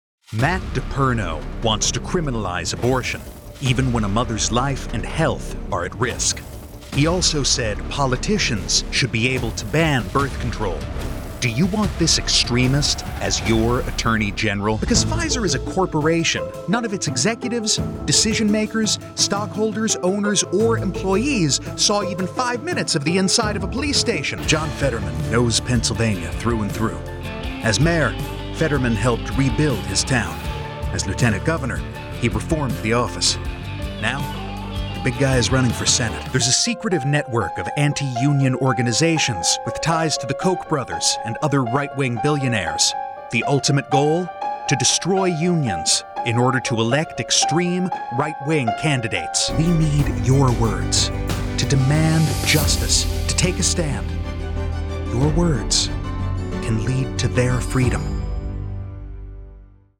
Male
English (North American)
Yng Adult (18-29), Adult (30-50)
Political Spots
Political Demo